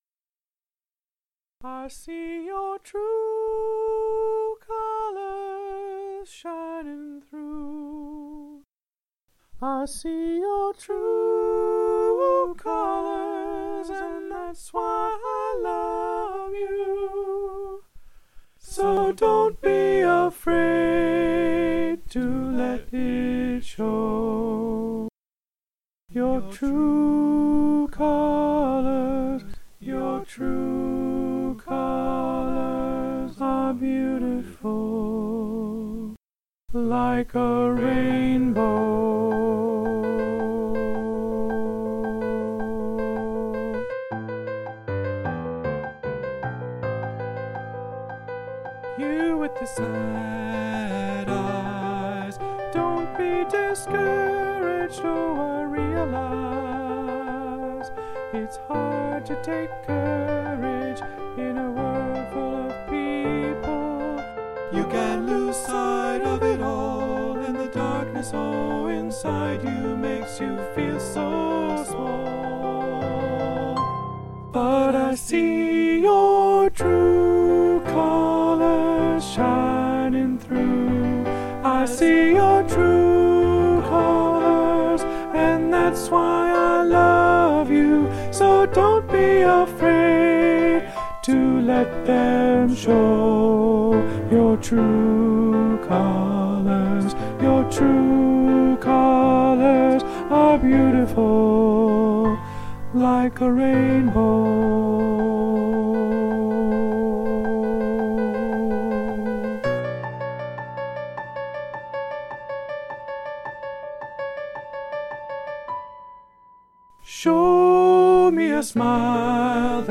True_Colors.alto2.mp3